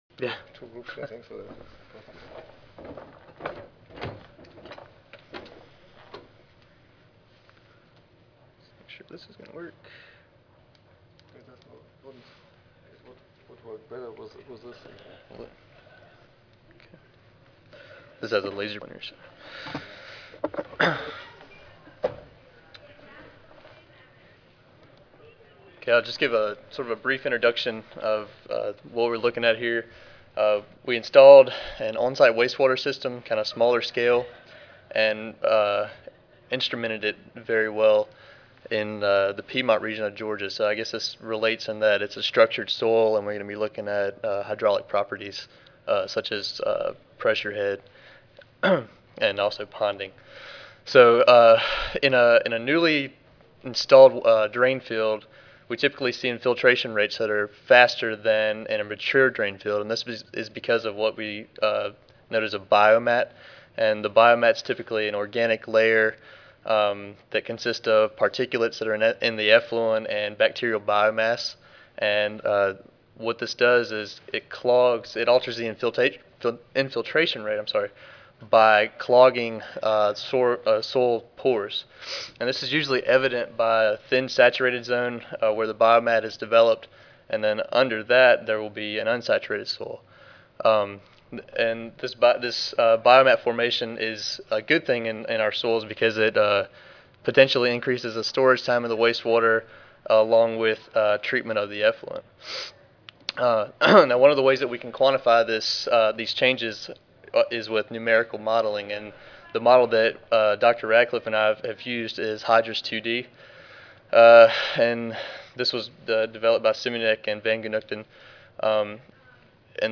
Univ. of Georgia Audio File Recorded presentation 4:30 PM Adjourn << Previous Session